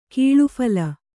♪ kīḷuphala